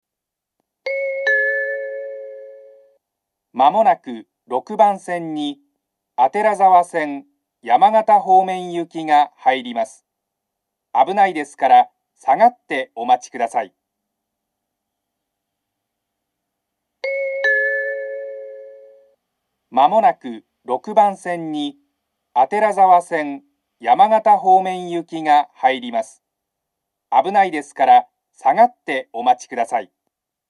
６番線上り接近放送